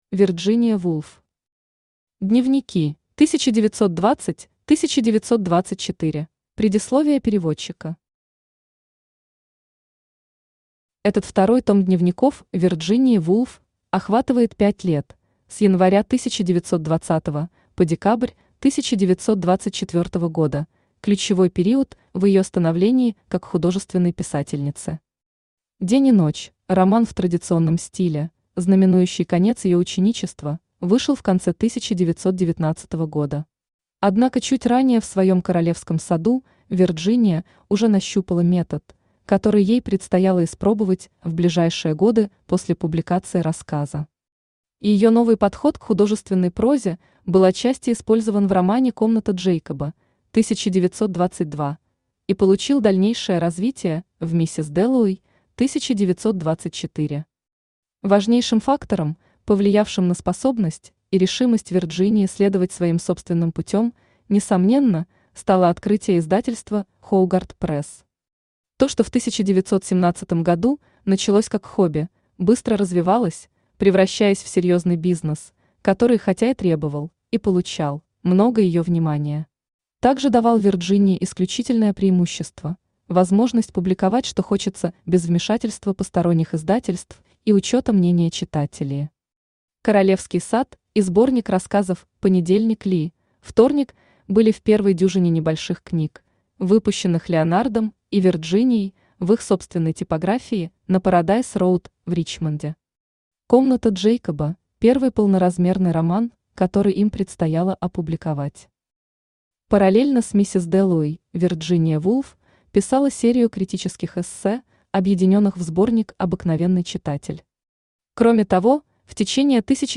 Аудиокнига Дневники: 1920–1924 | Библиотека аудиокниг
Aудиокнига Дневники: 1920–1924 Автор Вирджиния Вулф Читает аудиокнигу Авточтец ЛитРес.